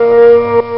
buzwarn.wav